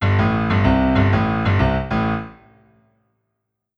Over 320 samples beats & sounds
PIANO-95B-03.WAV